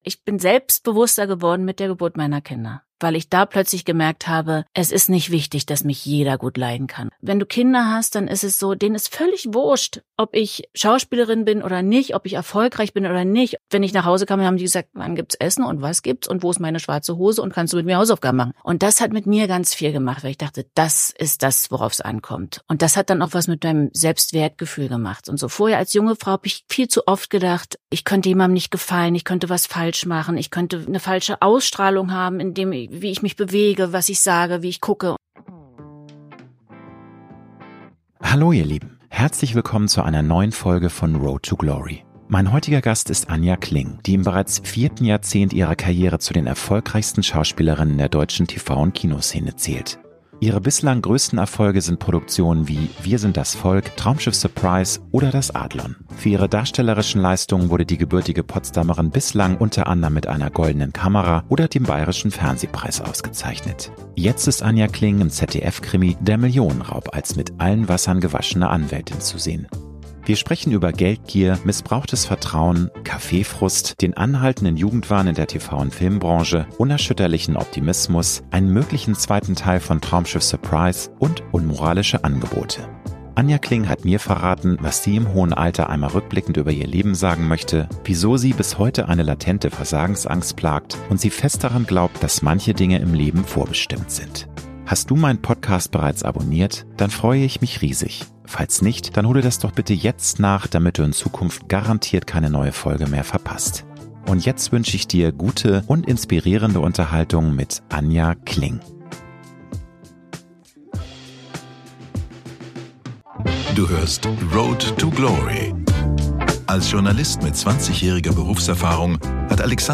Mein heutiger Gast ist Anja Kling, die im bereits vierten Jahrzehnt ihrer Karriere zu den erfolgreichsten Schauspielerinnen in der deutschen TV- und Kinoszene zählt.